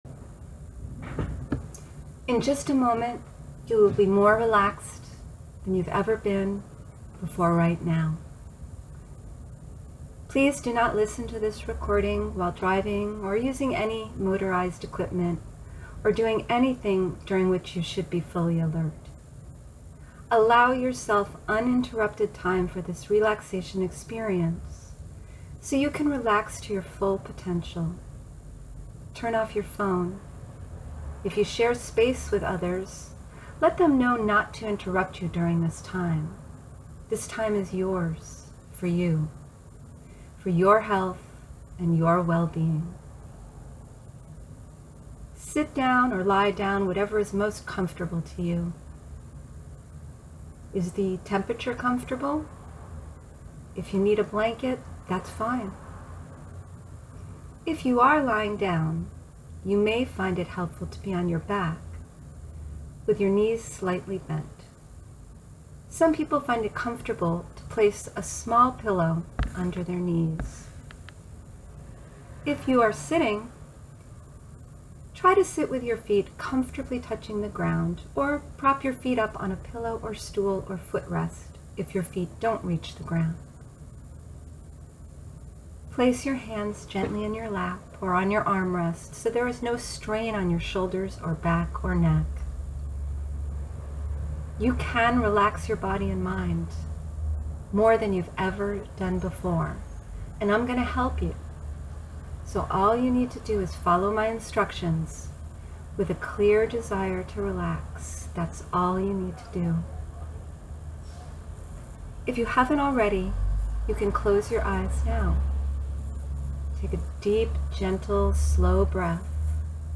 Guided Imagination, sometimes called “guided imagery” or “guided visualization” is a set of directions, or suggestions, that guide you into a relaxed state.
This audio relaxation technique uses the imagery of a walk through the woods, a stream and a meadow.
guided_imagination.mp3